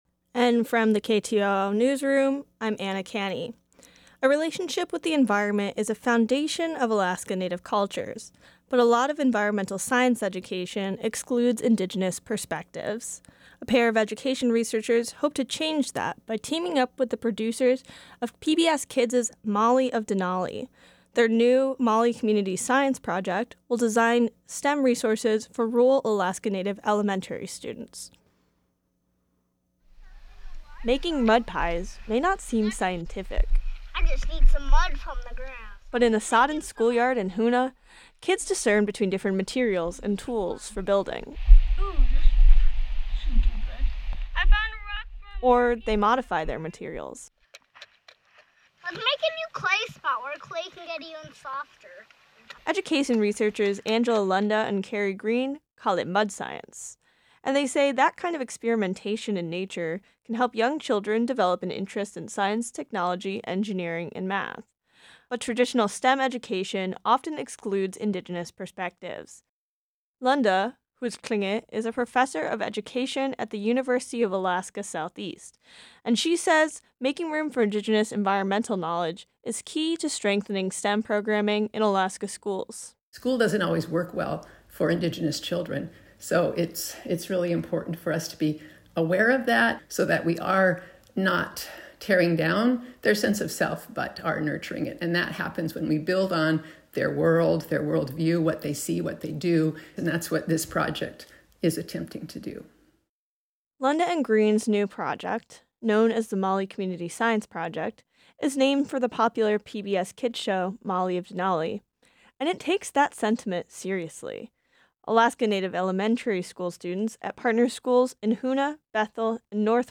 Newscast – Thursday, Sept. 28, 2023